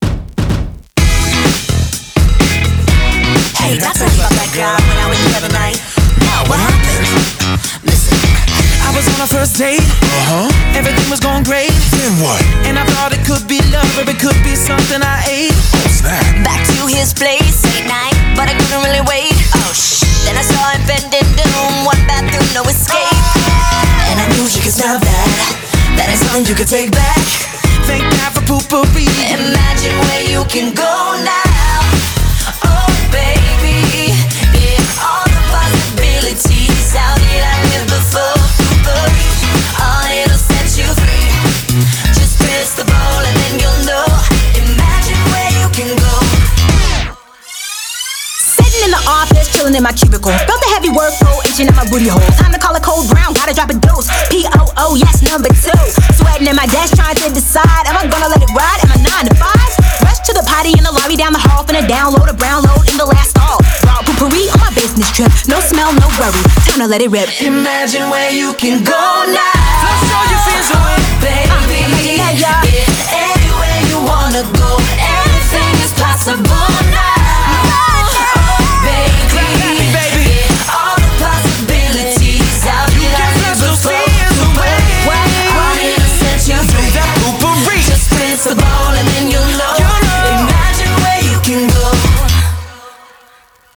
BPM126
Audio QualityPerfect (High Quality)
126 BPM